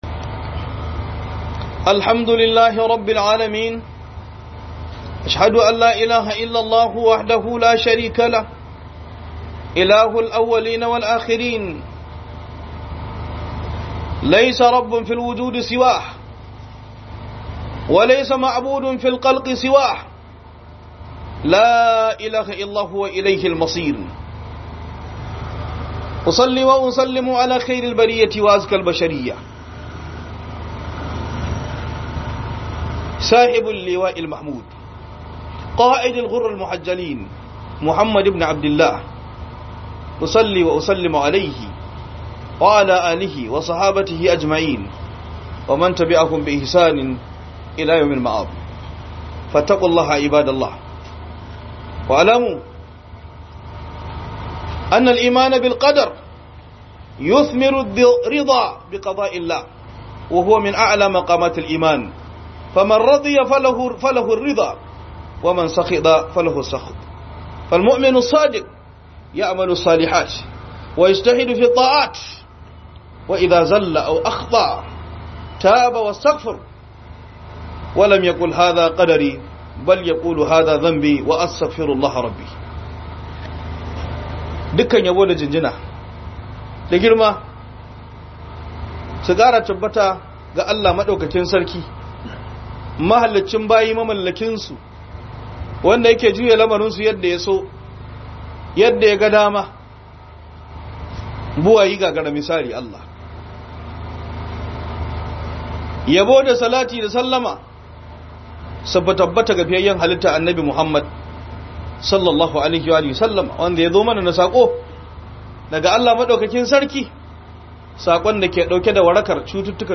Book HUDUBA